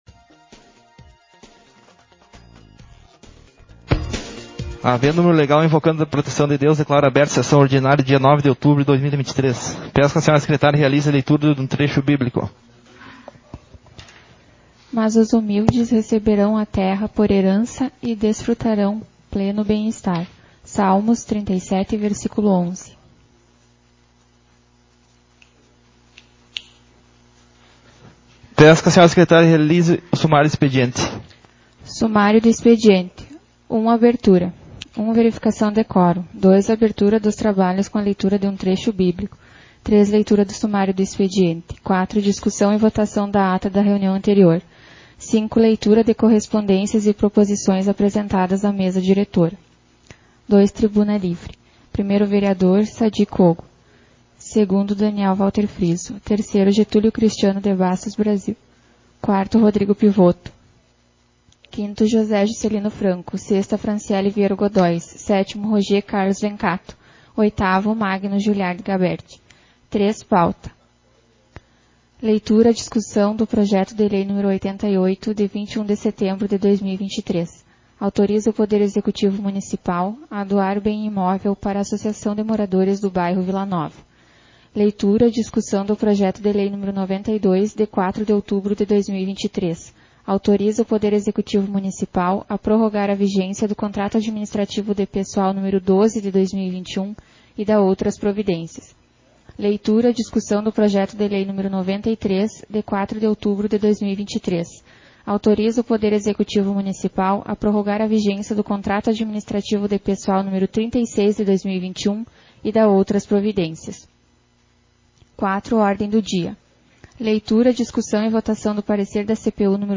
Sessão Ordinária 33/2023